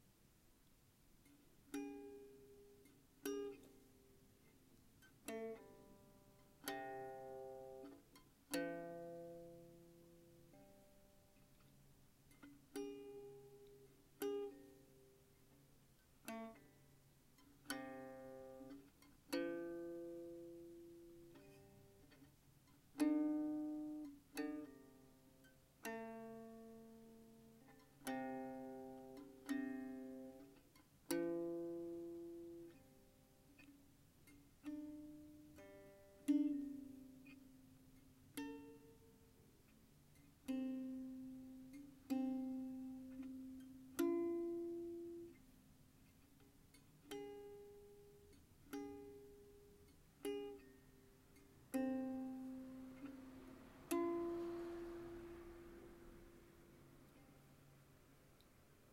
三味線
駒：象牙（オリジナル）